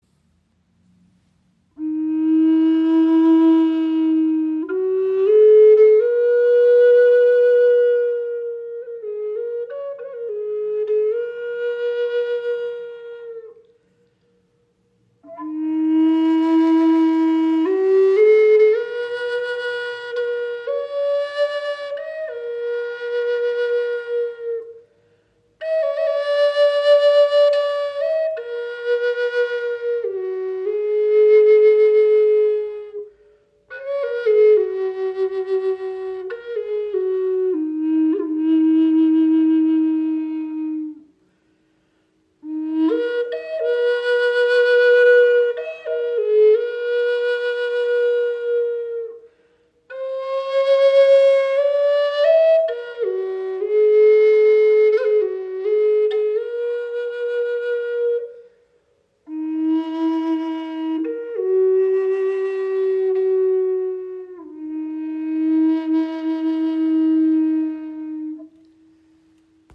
High Spirit Gebetsflöte Earth Tone in tiefem E - 432 Hz | spanische Zeder
• Icon Gestimmt auf 432 Hz – für besonders harmonischen, erdenden Klang
Diese EarthTone Bassflöte in E-Moll (432 Hz) aus aromatischer Zeder überzeugt mit einem warmen, erdigen Klang und einer sanften Tiefe.
Das weiche Holz verleiht ihr einen besonders warmen, erdverbundenen Klangcharakter.
Obwohl sie mit ihrer Tiefe besticht, bleibt sie auch in den höheren Registern tragfähig und kann wunderbar als Lead-Instrument eingesetzt werden.